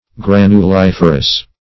Search Result for " granuliferous" : Wordnet 3.0 ADJECTIVE (1) 1. producing or full of granules ; The Collaborative International Dictionary of English v.0.48: Granuliferous \Gran`u*lif"er*ous\, a. [Granule + -ferous.]
granuliferous.mp3